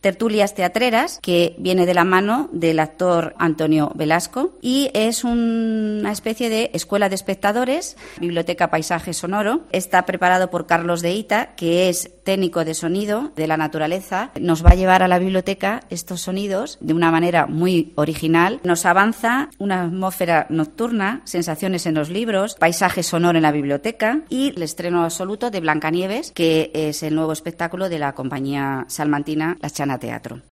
La concejala Mª Victoria Bermejo informa de la programación cultural